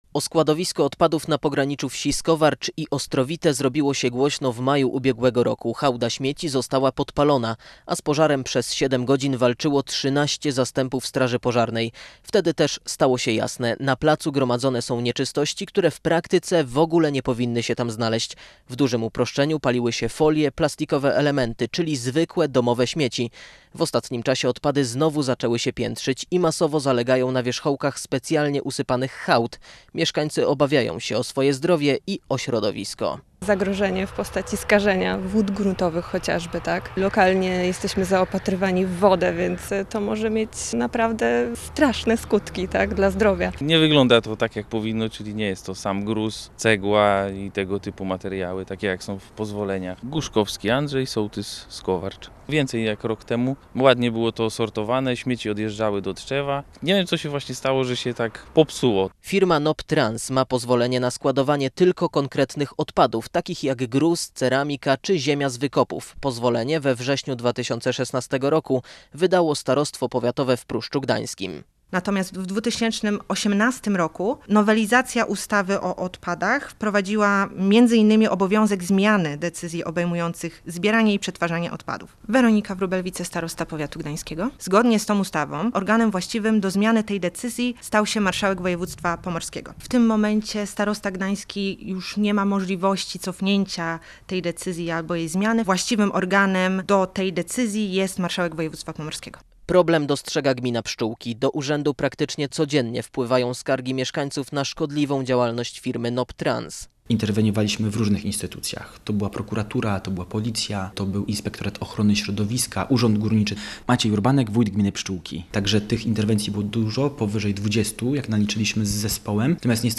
W rozmowie z naszym reporterem mieszkańcy Skowarcza przyznają, że jeszcze do niedawna byli w stanie przymykać oko na – ich zdaniem – nielegalny proceder.